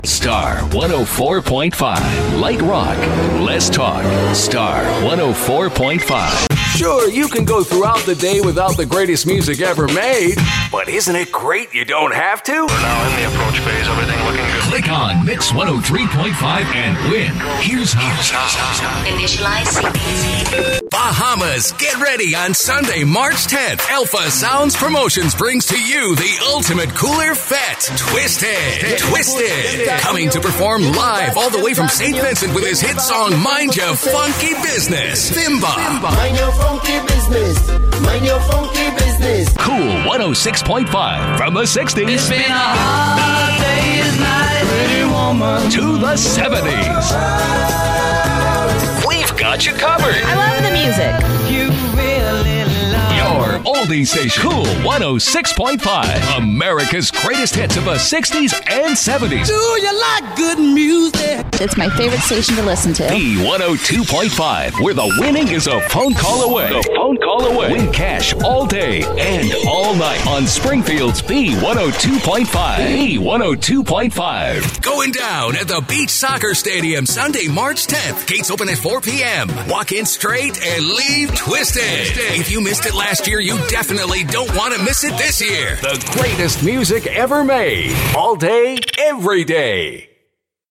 Male
American English (Native)
Authoritative, Confident, Cool, Deep, Engaging, Friendly, Gravitas, Natural, Smooth, Warm
Documentary.mp3
Microphone: Neumann TLM -49